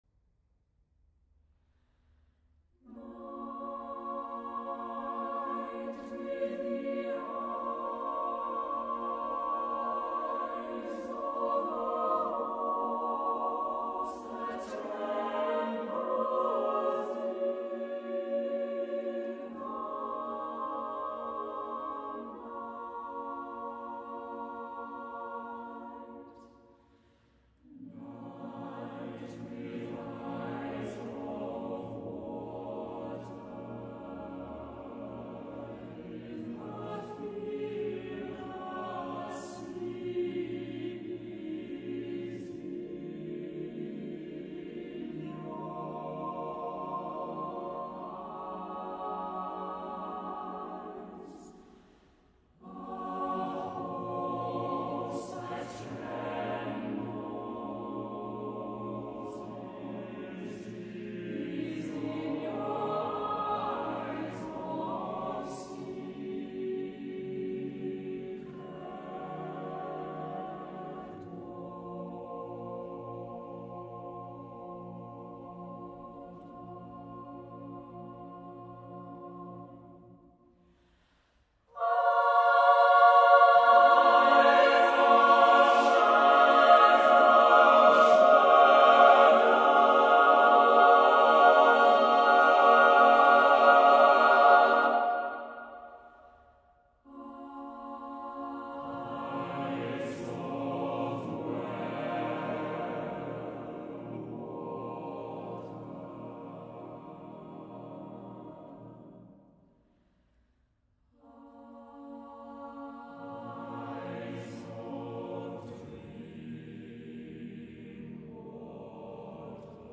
Accompaniment:      A Cappella
Music Category:      Choral